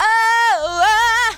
OH AAH.wav